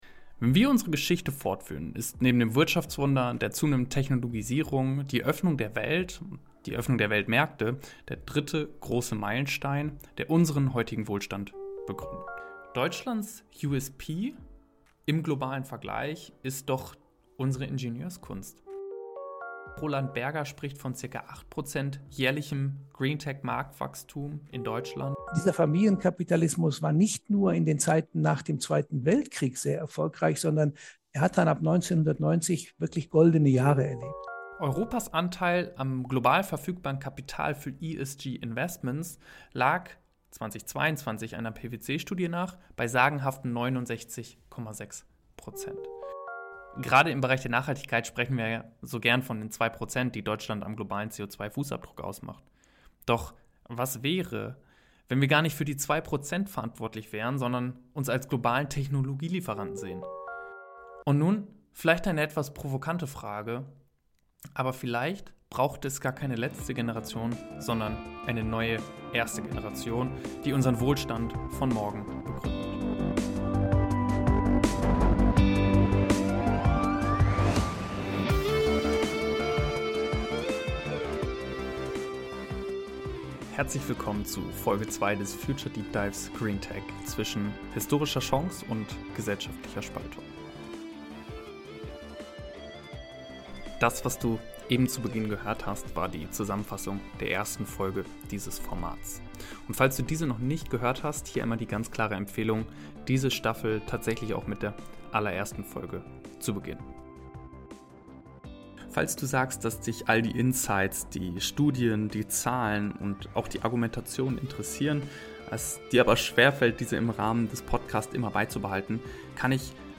Wir möchten Licht ins Dunkle bringen und über die nachhaltige Transformation in industriellen Unternehmen aufklären. Diese Serie besteht aus fünf Folgen für die verschiedenste, spannende Menschen interviewt wurden.